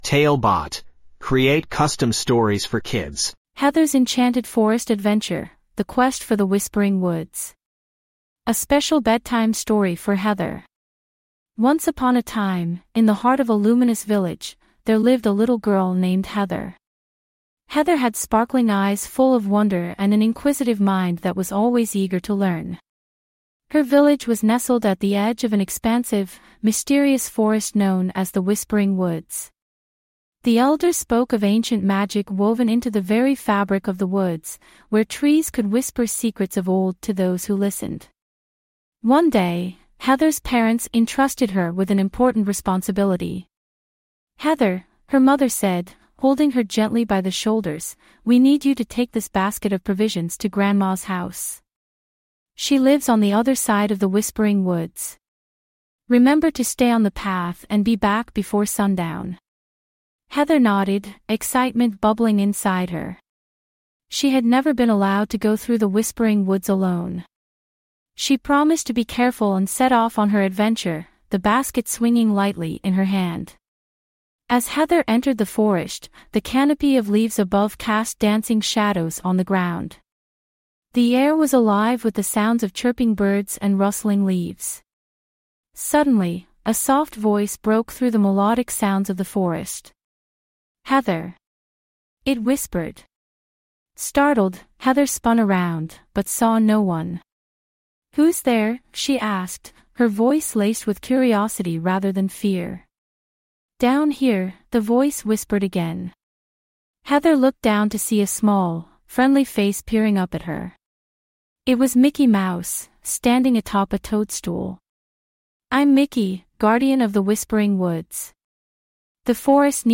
5 minute bedtime stories.